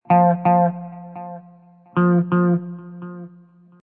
Descarga de Sonidos mp3 Gratis: guitarra.